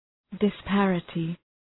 Shkrimi fonetik {dı’spærətı}